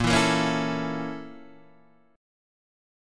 A#mM7b5 chord {6 7 8 6 5 5} chord